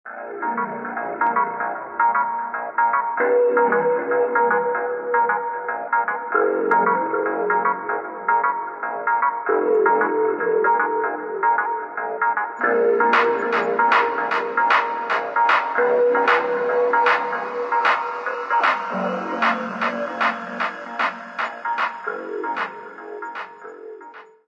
描述：8tr Tape Sounds.
标签： 哲学的 多元的 科学的 艺术的 梦幻般的 磁带 未来派 神奇 概念
声道立体声